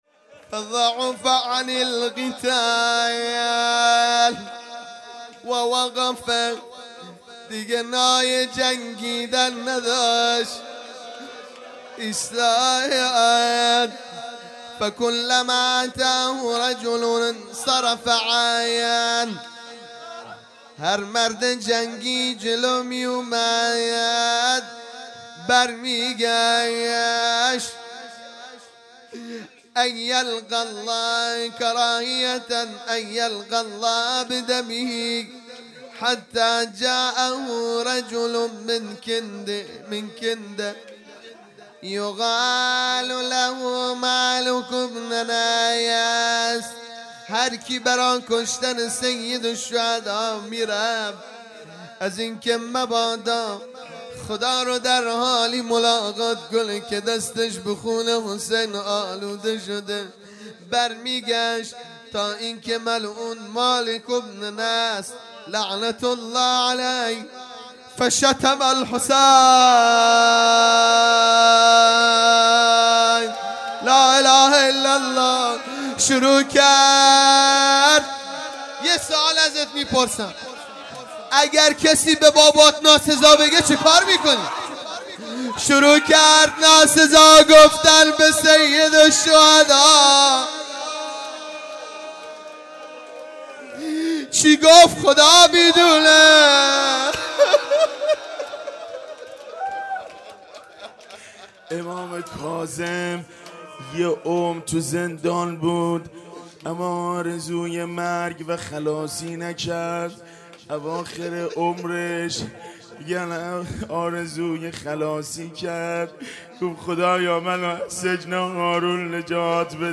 صوت های مراسم ظهر عاشورا محرم الحرام 95
روضه بخش سوم